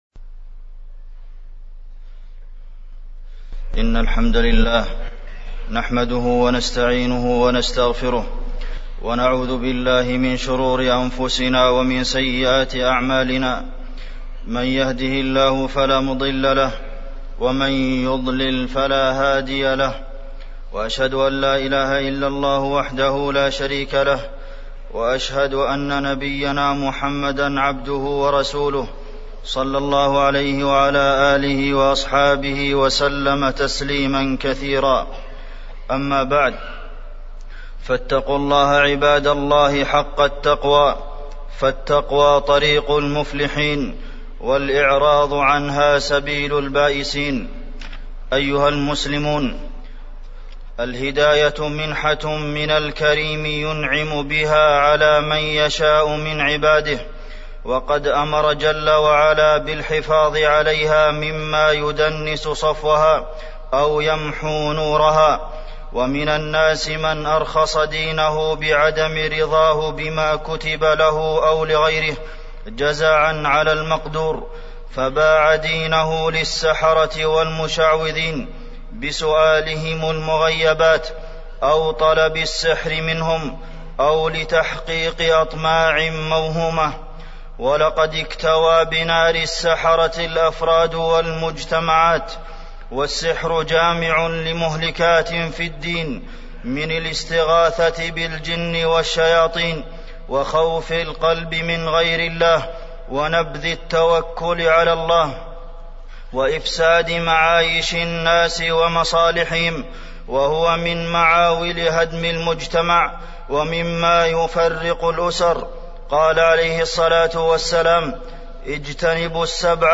تاريخ النشر ٢٠ ربيع الأول ١٤٢٦ هـ المكان: المسجد النبوي الشيخ: فضيلة الشيخ د. عبدالمحسن بن محمد القاسم فضيلة الشيخ د. عبدالمحسن بن محمد القاسم السحر